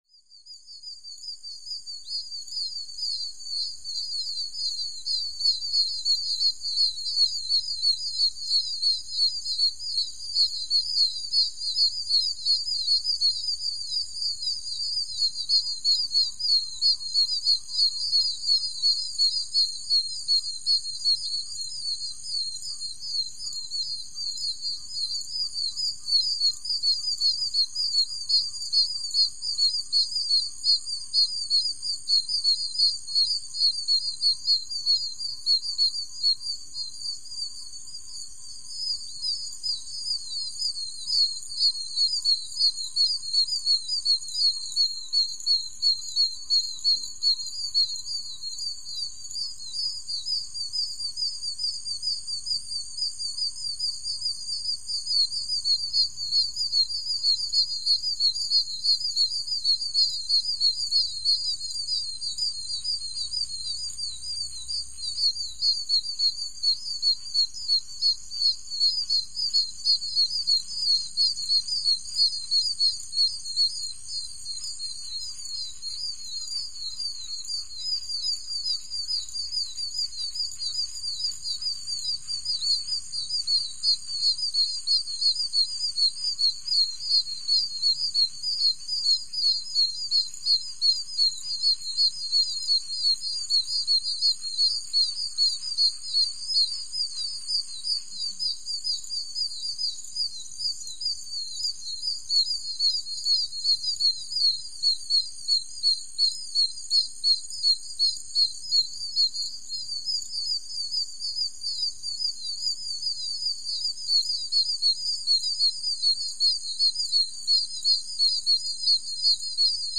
Florida Bullfrogs | Sneak On The Lot
Hi-pitched Florida Bullfrog Calls And Distant Cricket Bed. Could Use For Chick Peeps As Well.